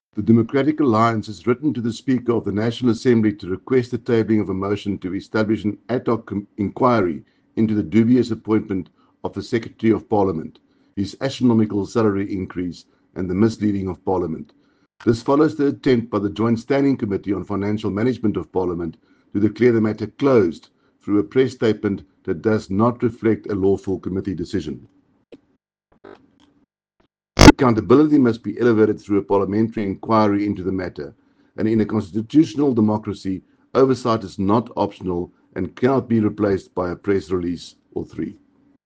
Soundbite Rikus Badenhorst MP.